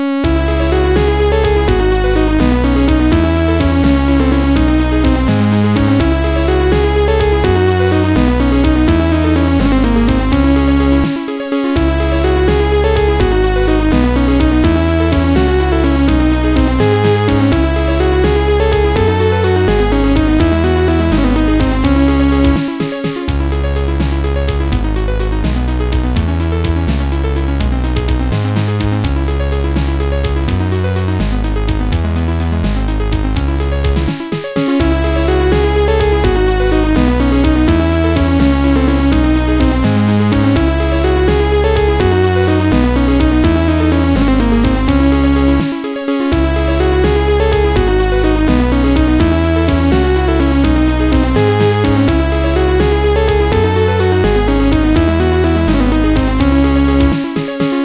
• Качество: 200, Stereo
мелодичные
без слов
Electronica